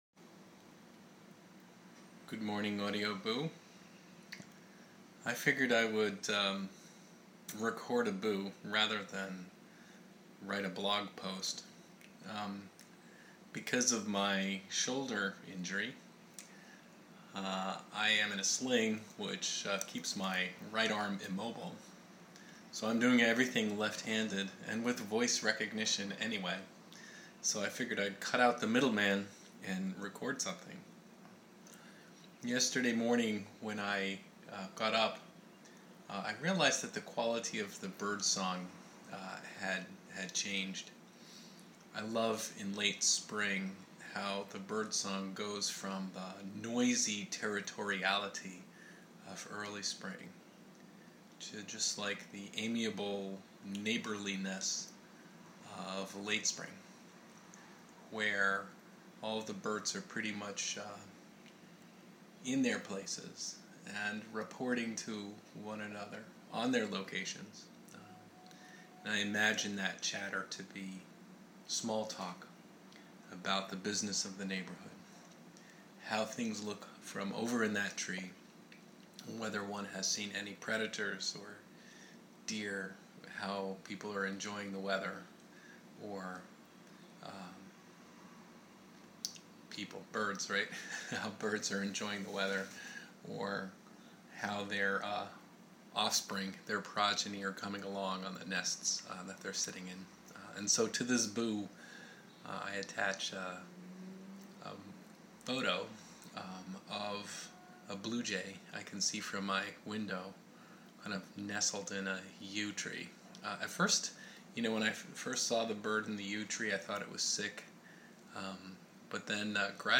The quality of birdsong changes in late spring.